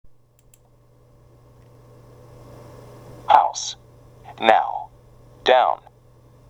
[アウ] house, now, down